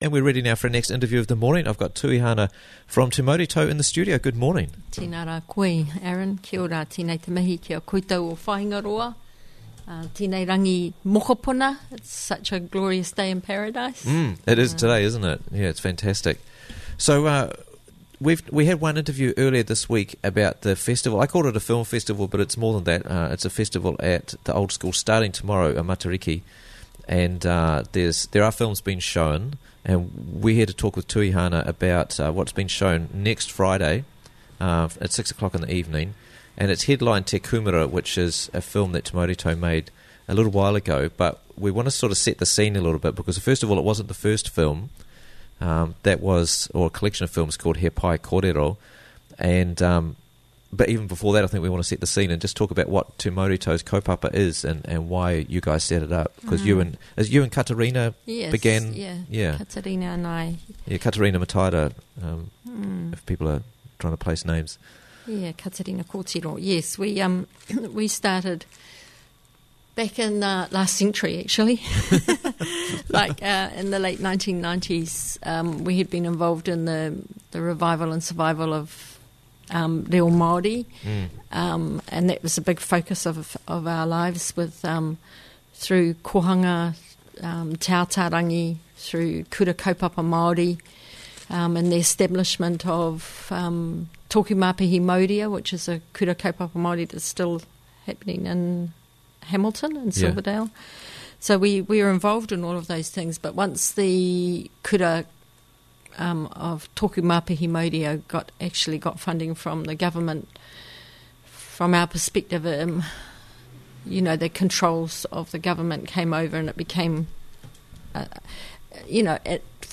Te Mauri Tau at Matariki Festival - Interviews from the Raglan Morning Show